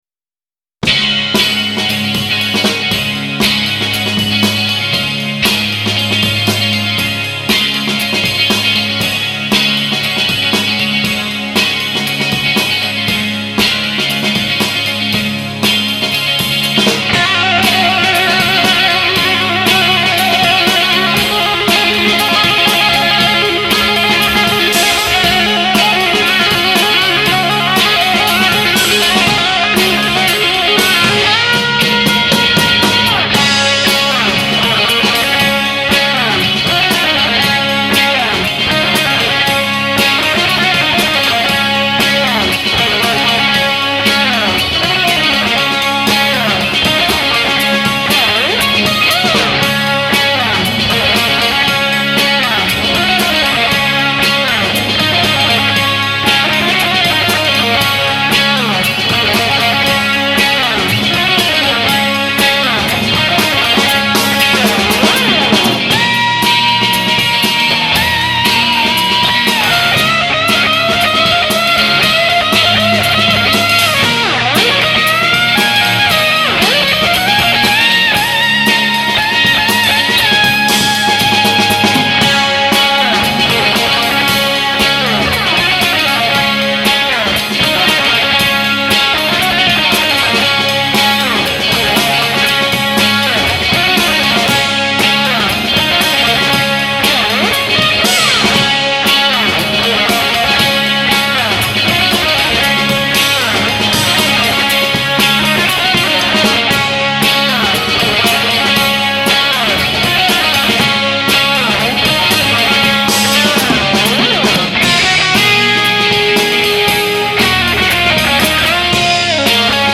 Guiter